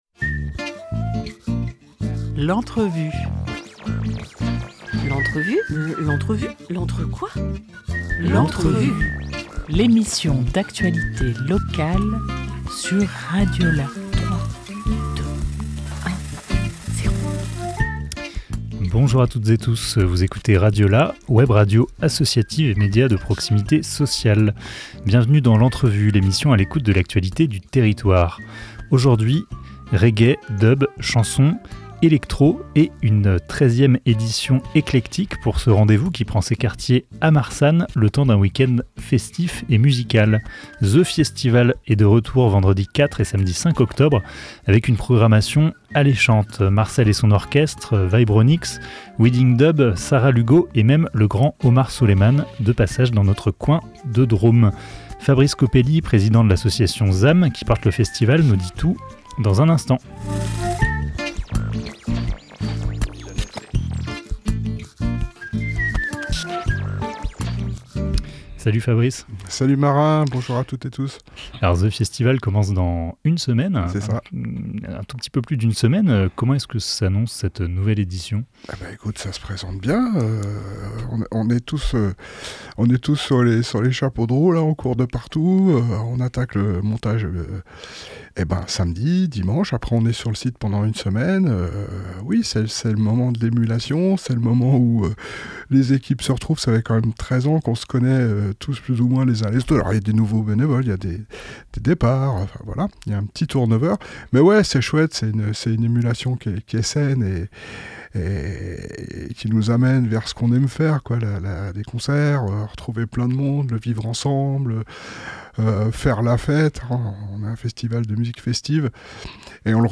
26 septembre 2024 11:33 | Interview